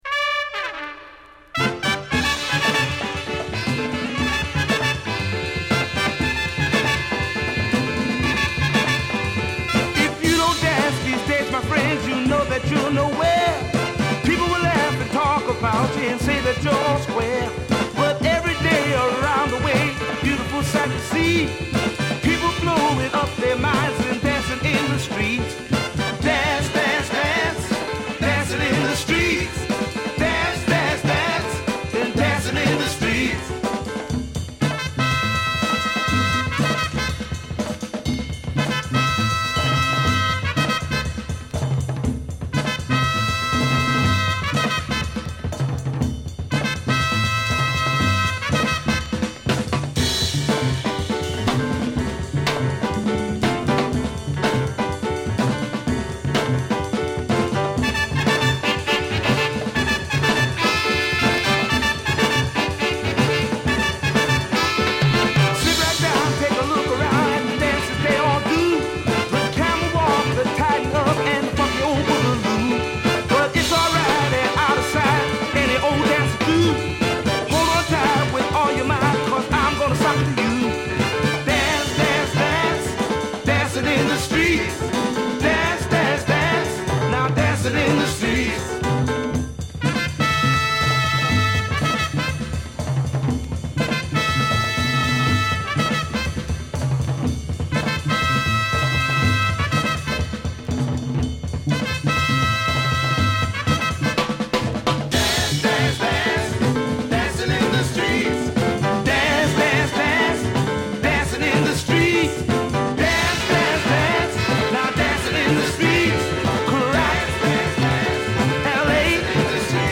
latin soul